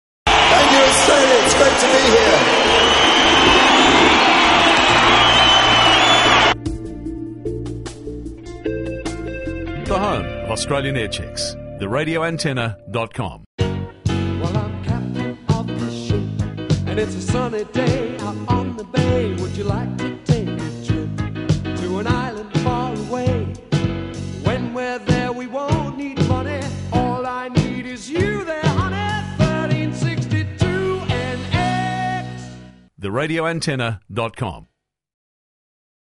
RA Aircheck -2NX Captain of the Ship
Featured Doc Neeson on the new intro offering prayers and comfort for the Doc at this time,